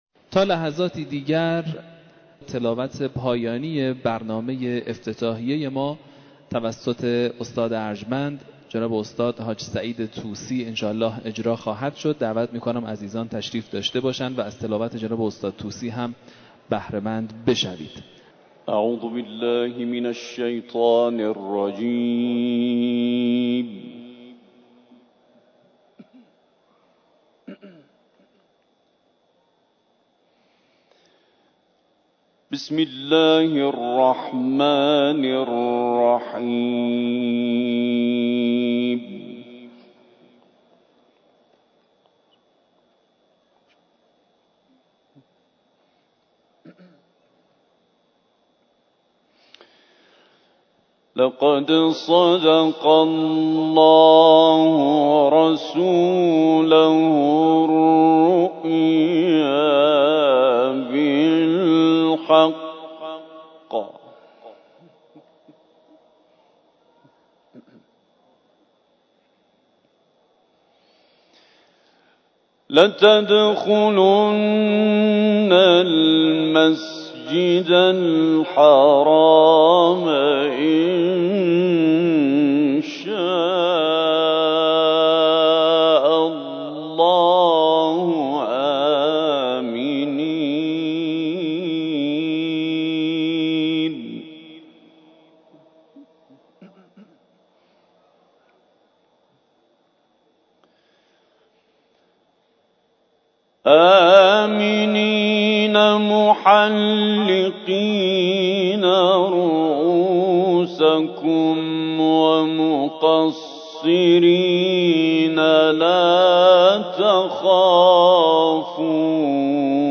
دانلود قرائت استاد طوسی در افتتاحیه سی‌ودومین دوره مسابقات بین‌المللی قرآن کریم
قرائت-استاد-طوسی-در-افتتاحیه-سی-و-دومین-دوره-مسابقات-قران-کریم.mp3